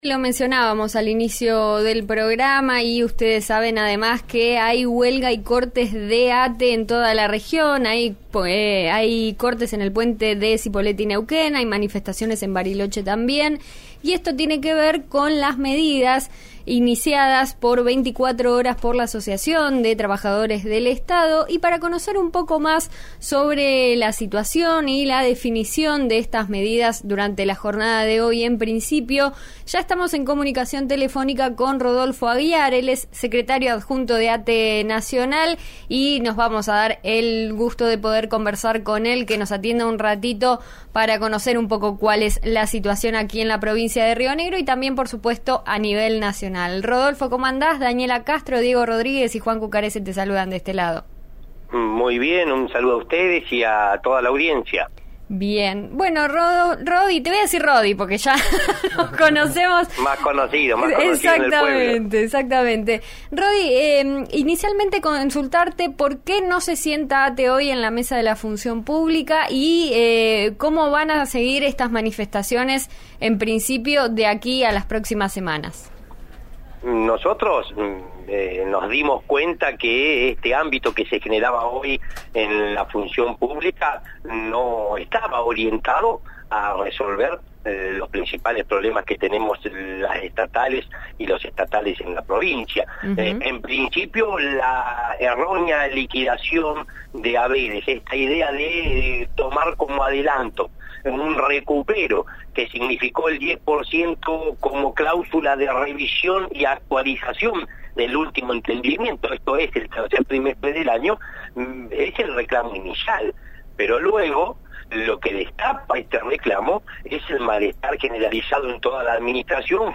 En diálogo con RN Radio